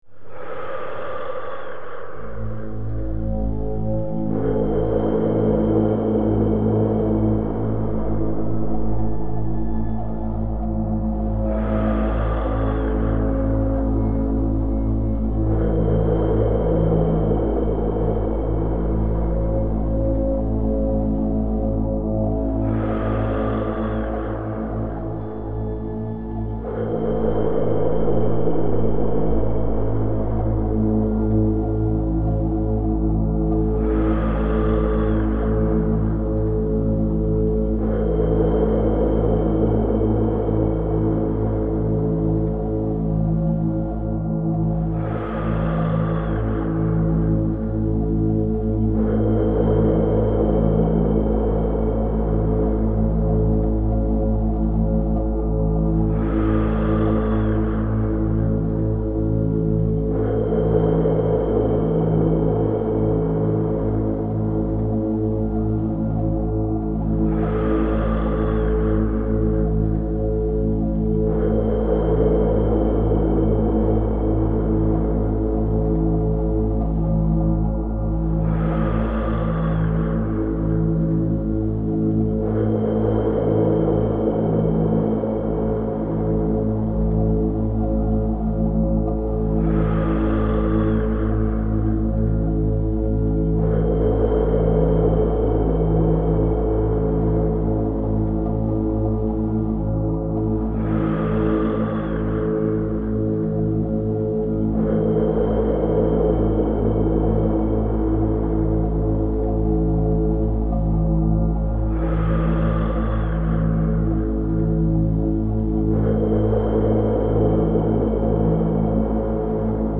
Suivre cet exercice de respiration